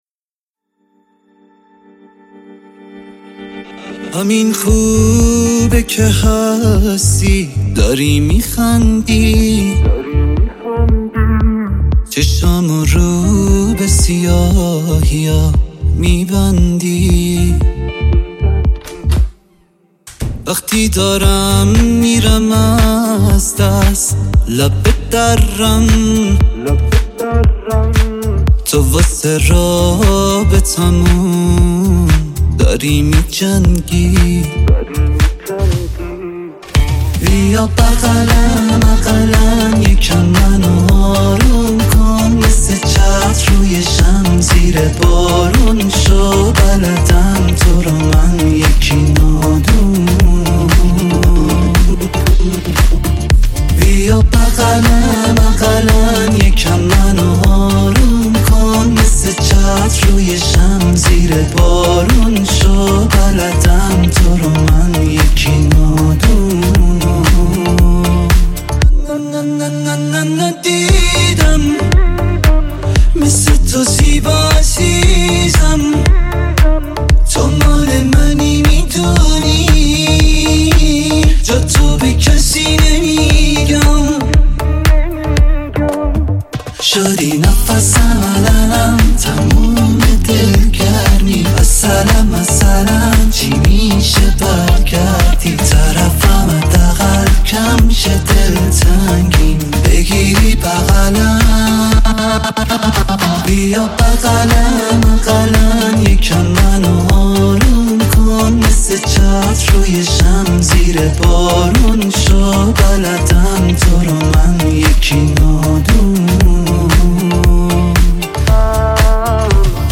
پاپ عاشقانه